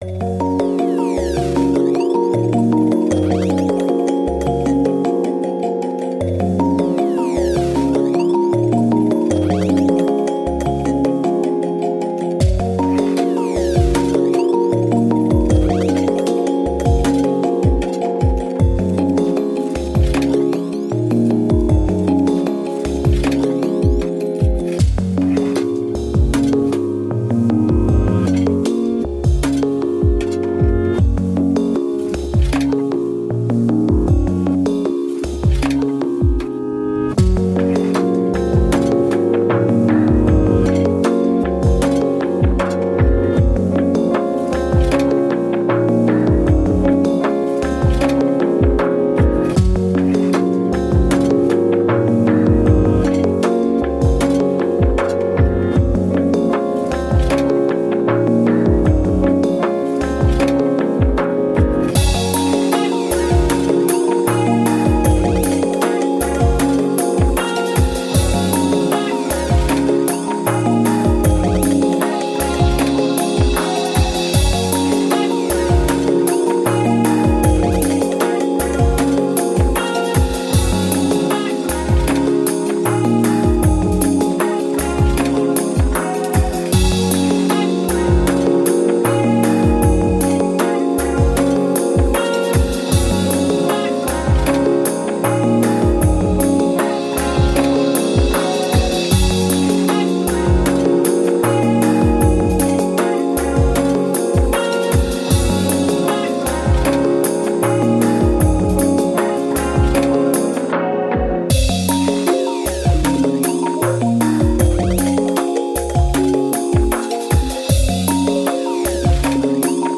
Electronic, IDM, Glitch, Thoughtful